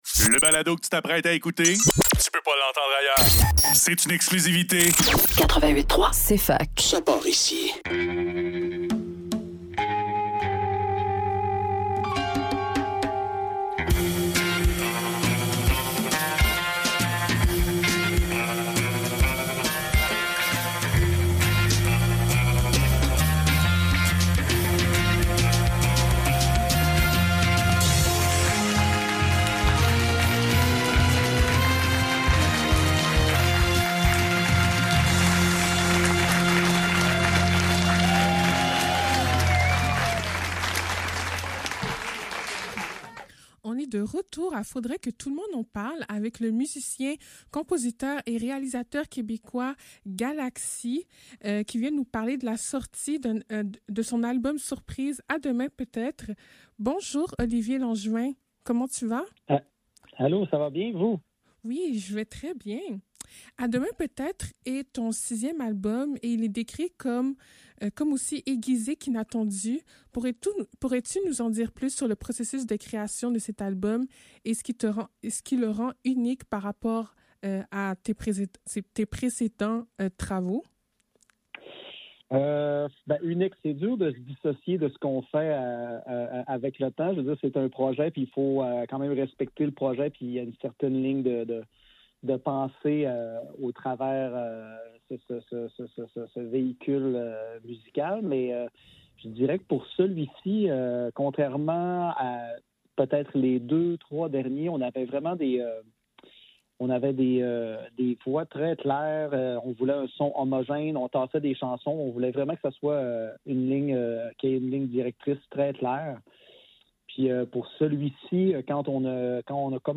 Faudrait que tout l'monde en parle - Entrevue avec Galaxie - 8 février 2024